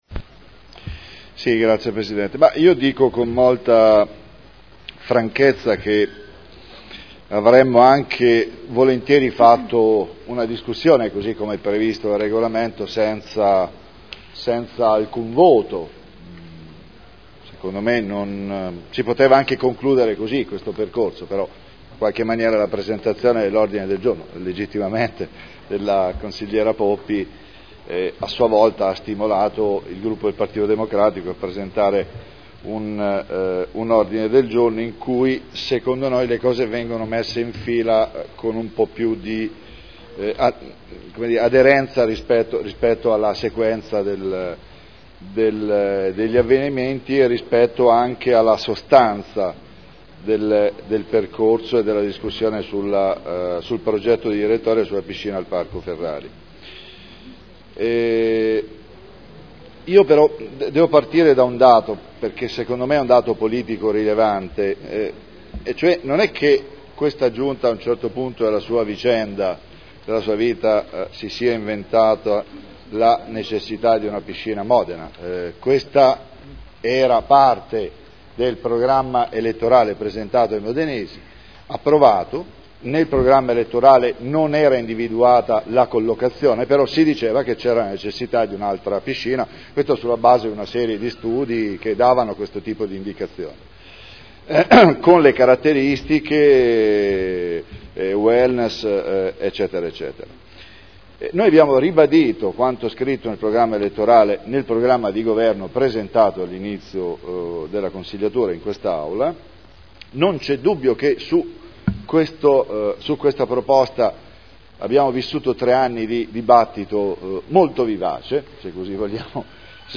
Seduta del 9/07/2012. Istruttoria pubblica Progetto Direttore del Parco Ferrari - Dibattito (art. 7 comma 5 Regolamento comunale sugli istituti di partecipazione). Presenta un ordine del giorno (81212)